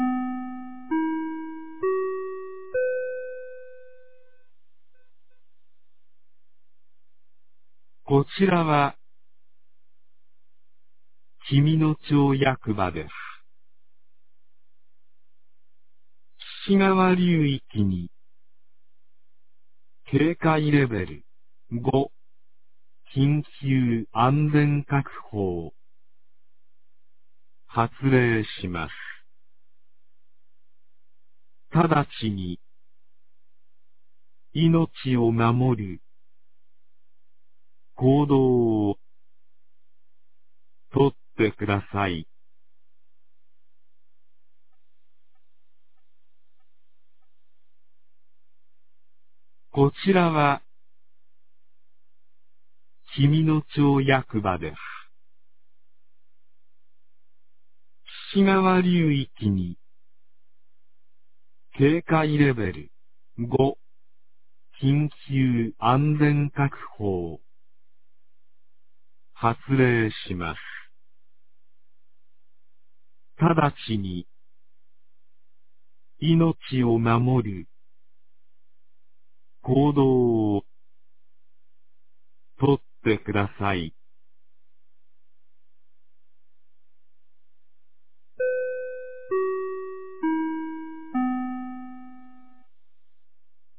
2023年06月02日 14時25分に、紀美野町より全地区へ放送がありました。